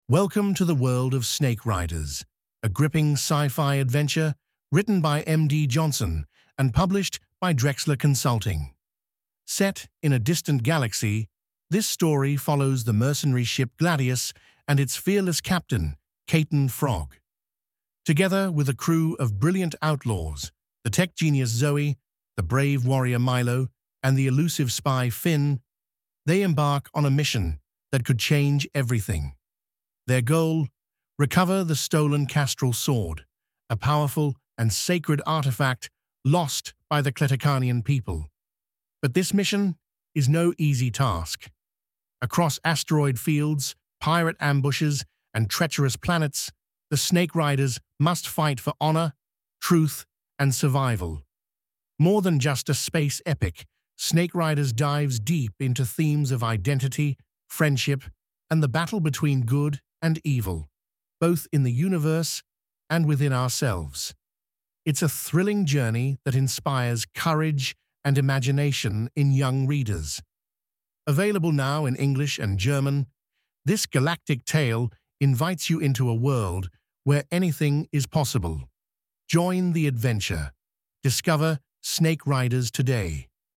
Barrier-free audio introduction about Snake Riders, a thrilling space epic for young adults.